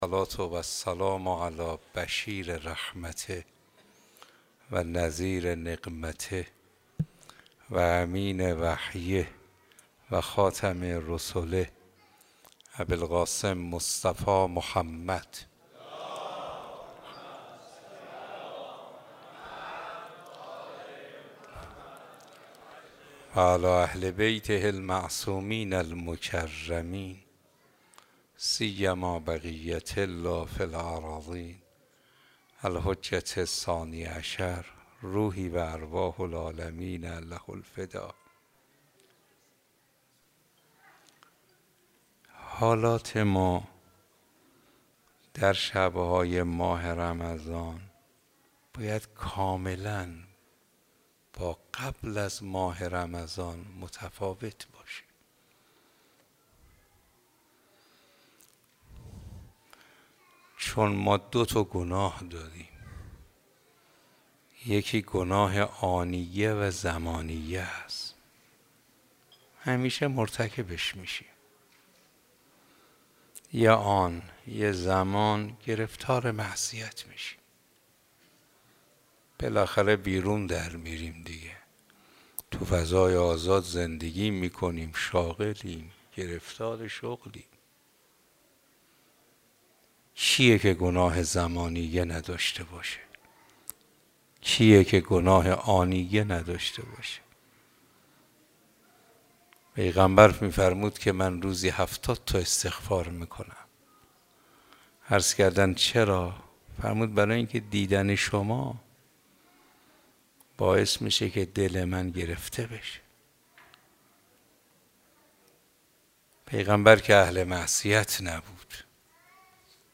این بخش، جلسه اول از پادکست سخنرانی‌های رمضان است که با نگاهی معرفتی و عرفانی تنظیم شده است.